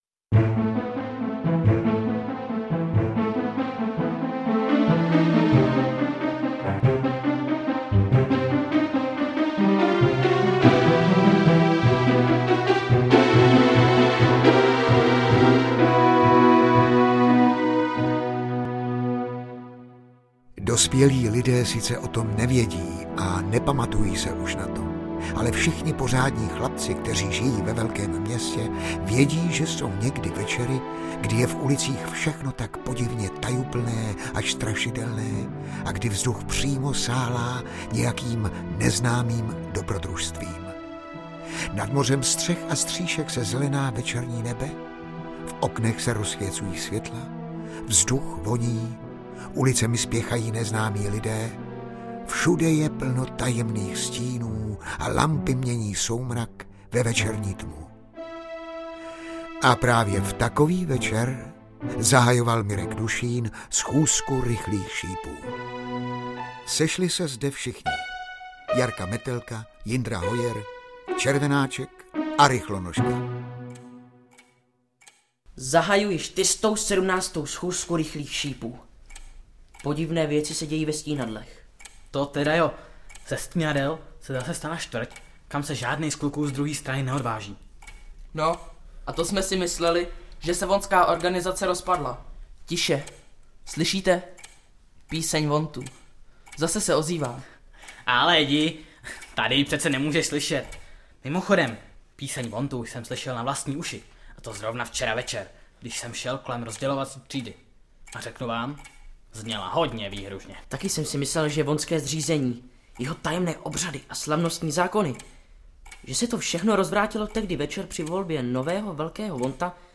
Titul vždy vzbudil velký rozruch a mnozí posluchači zatoužili poslechnout si další dramatizované příběhy Rychlých šípů.
Z audio knihy čiší levnost, amaterismus, nezvládnutá dramaturgie; naprosto ubohý je i zvukový doprovod.